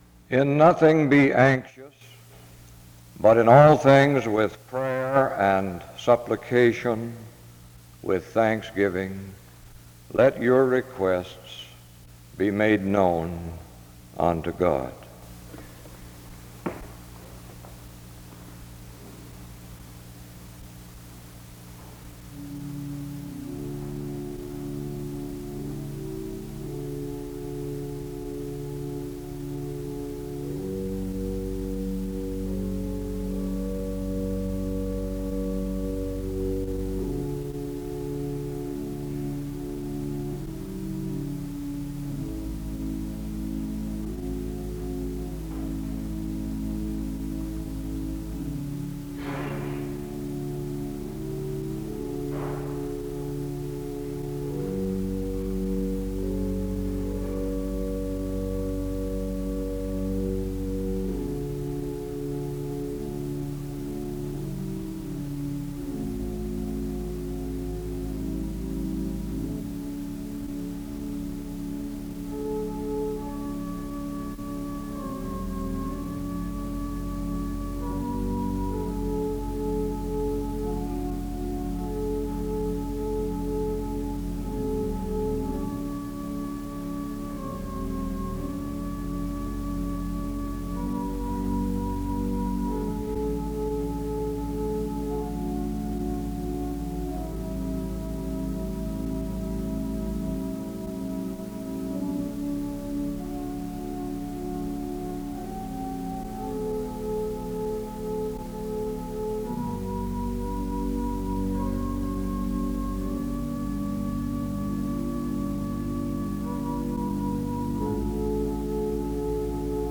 He prays from 4:46-6:07. Music plays from 6:11-9:24.